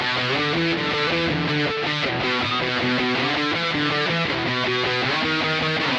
重型 电吉他1
Tag: 160 bpm Heavy Metal Loops Guitar Electric Loops 1.01 MB wav Key : E Pro Tools